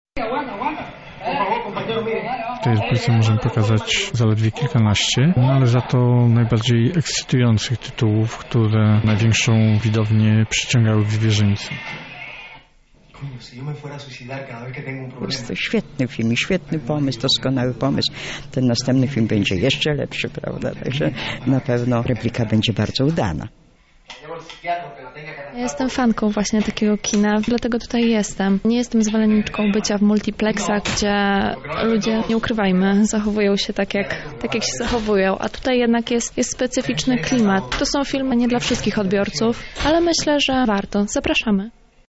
O swoich wrażeniach opowiadają także widzowie.